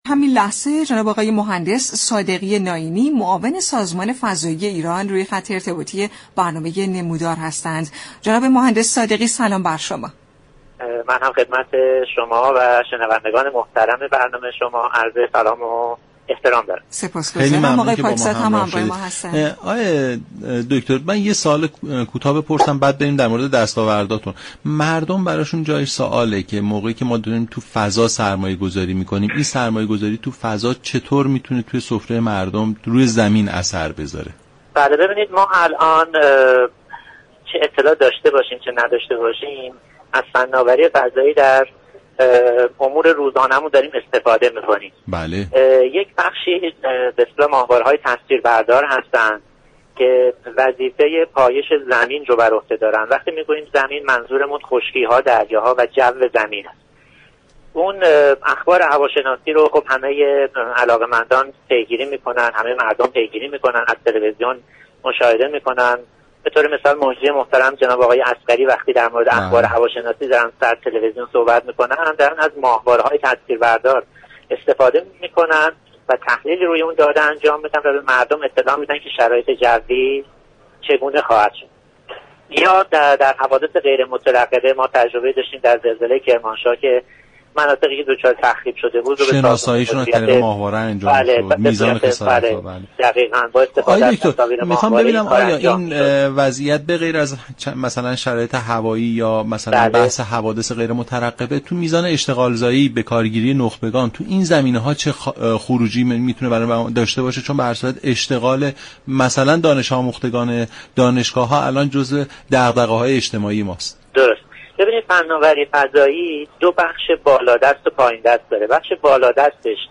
«مهندس علی صادقی نائینی» معاون سازمان فضایی ایران در برنامه «نمودار» رادیو ایران گفت : در حوادث غیرمترقبه و در زمان زلزله و شناسایی مناطق آسیب دیده از ماهواره ها استفاده زیادی میشود